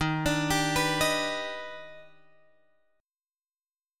D# Augmented Major 7th